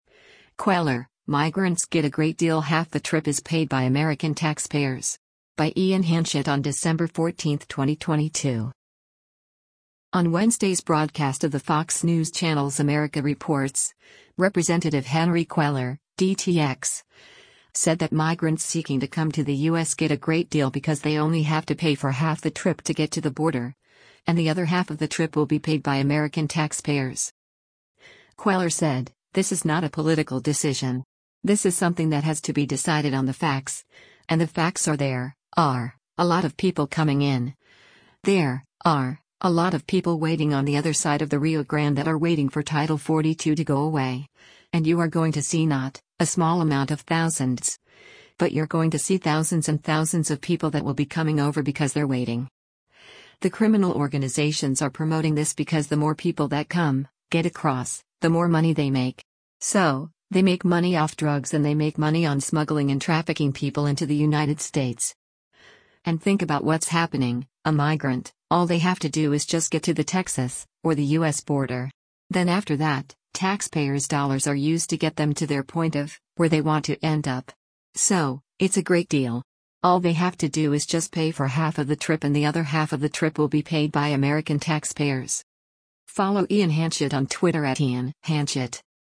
On Wednesday’s broadcast of the Fox News Channel’s “America Reports,” Rep. Henry Cuellar (D-TX) said that migrants seeking to come to the U.S. get “a great deal” because they only have to pay for half the trip to get to the border, “and the other half of the trip will be paid by American taxpayers.”